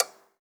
clock_tock_02.wav